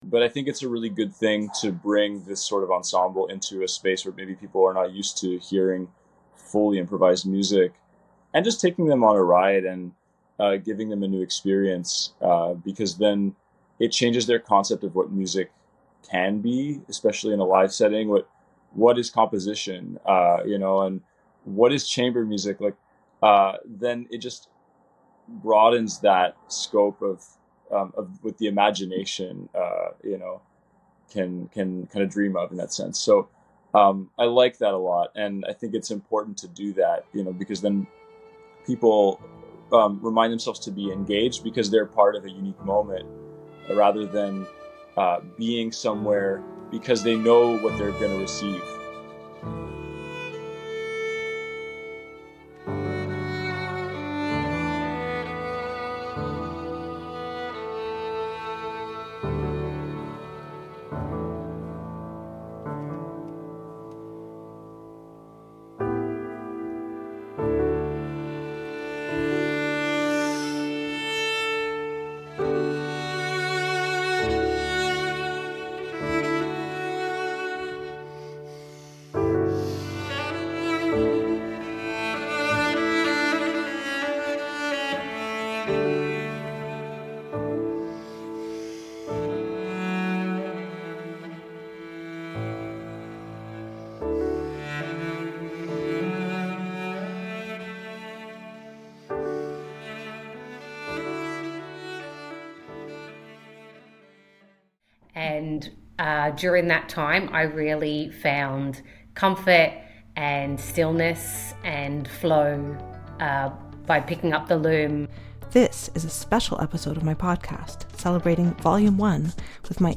We are including excerpts from the album during this episode, and you’ll find all the links to buy and stream this music below.